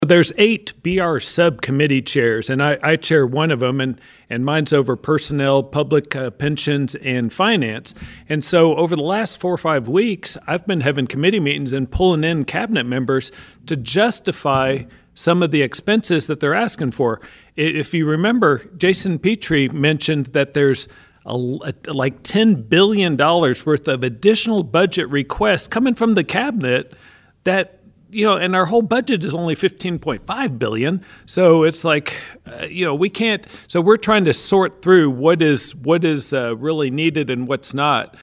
Third District State Senator Craig Richardson was joined by State Representatives Mary Beth Imes, Walker Thomas, and Myron Dossett during the Your News Edge Legislative Update program Saturday that was recorded at the H&R Agri-Power Pancake Day.